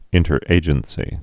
(ĭntər-ājən-sē)